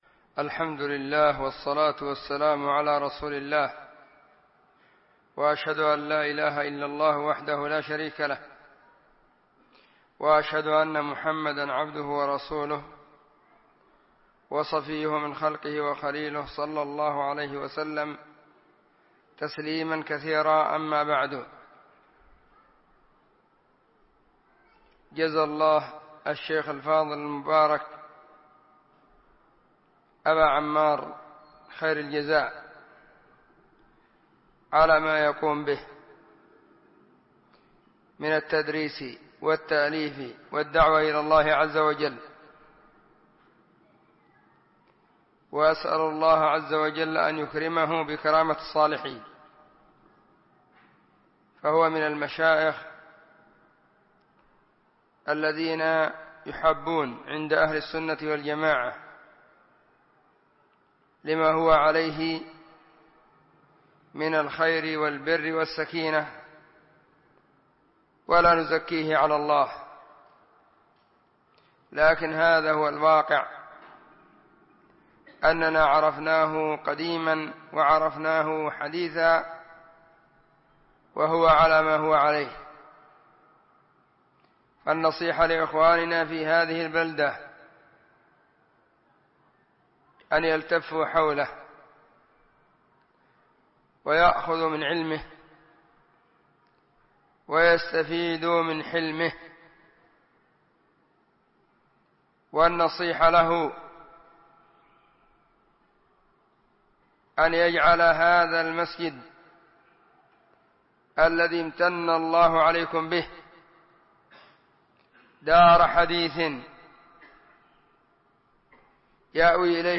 محاضرة بعنوان:واجتنبوا قول الزور.
📢 مسجد الصحابة – بالغيضة – المهرة، اليمن حرسها •اللّـہ̣̥.